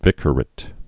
(vĭkər-ĭt, -ə-rāt)